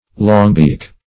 Search Result for " longbeak" : The Collaborative International Dictionary of English v.0.48: Longbeak \Long"beak`\, n. (Zool.)